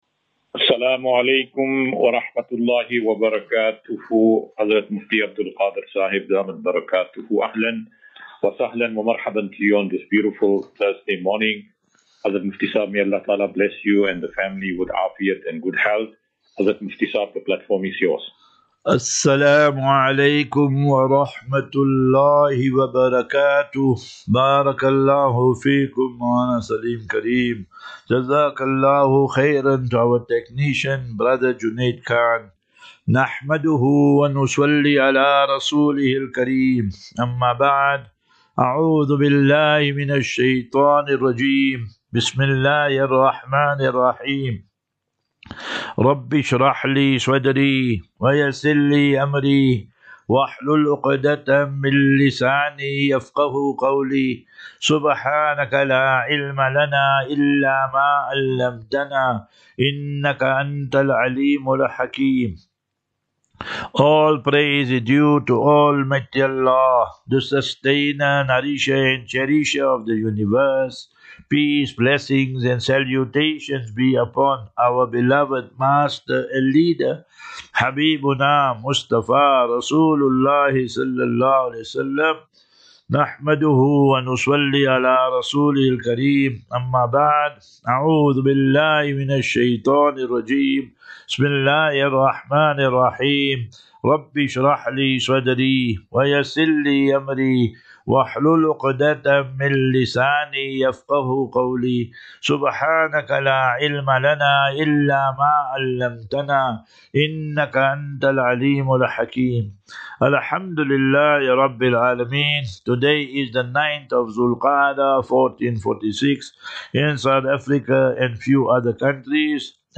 Assafinatu - Illal - Jannah. QnA
Daily Naseeha.